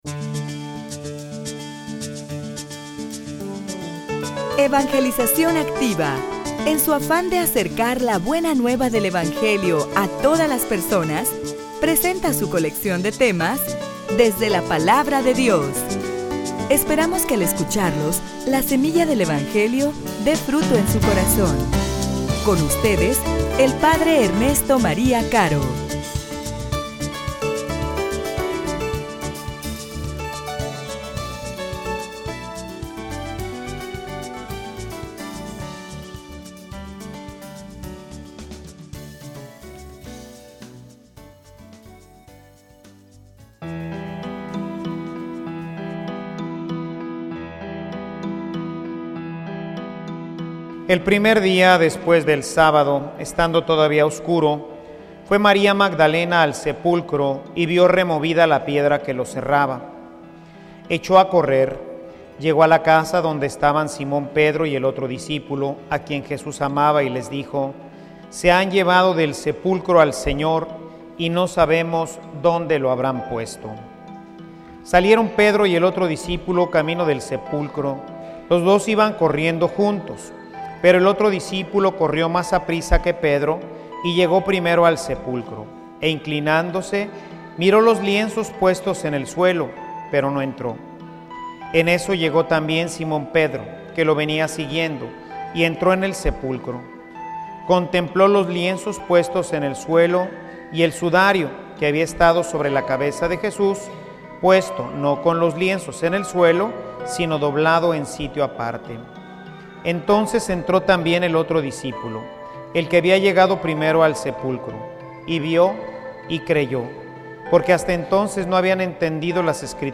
homilia_El_premio_a_la_perseverancia_la_Victoria.mp3